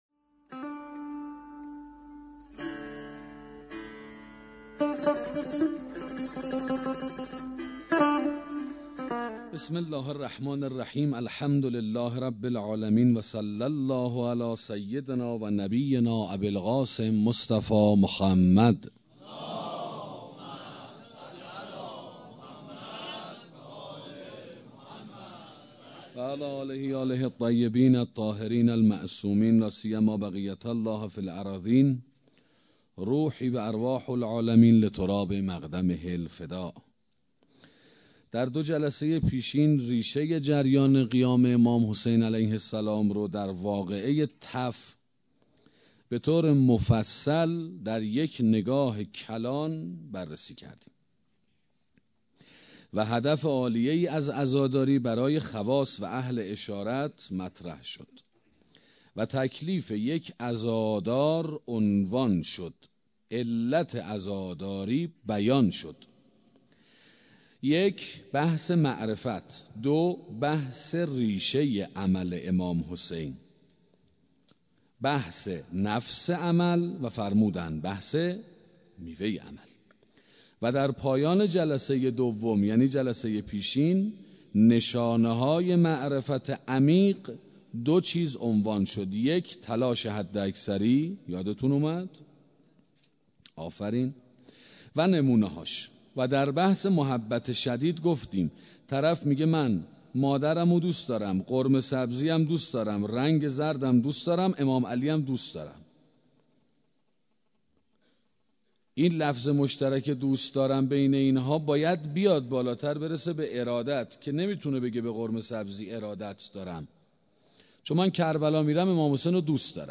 سخنرانی معرفت حسینی 3